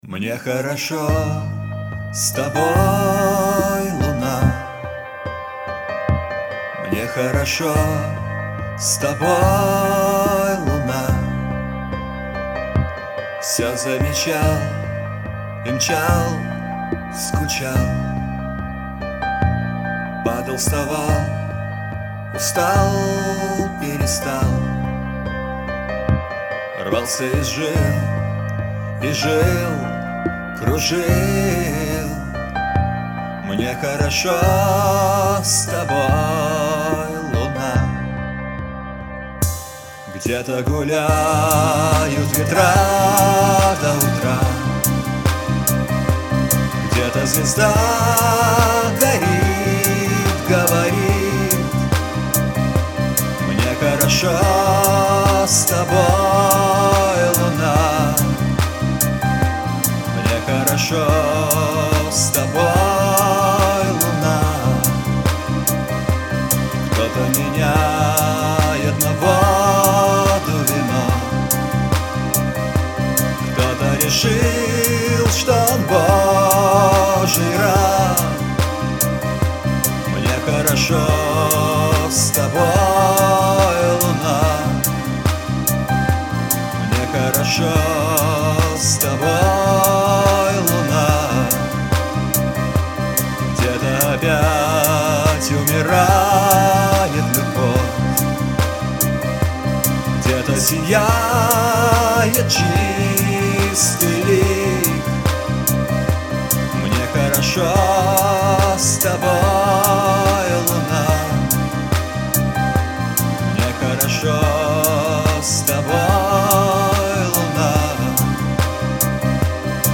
Альбом записан на домашней студии.